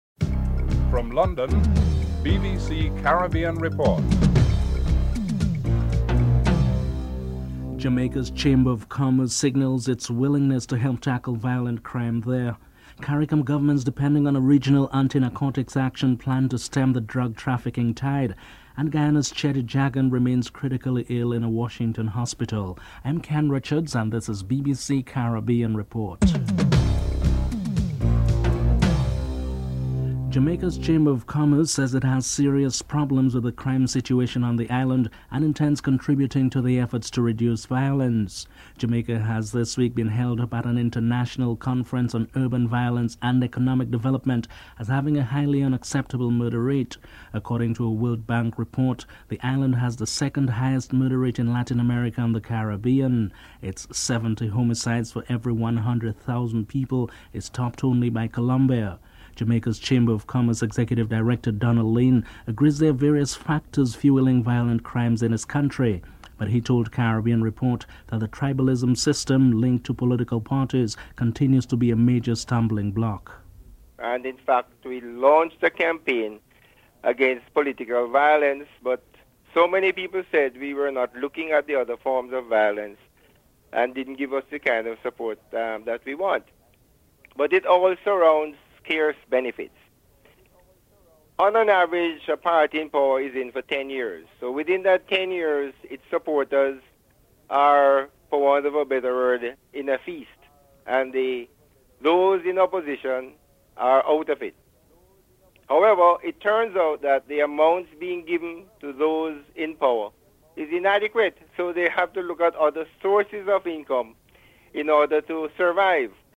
1. Headlines (00:00-00:28)
P.J. Patterson is interviewed (03:58-05:42)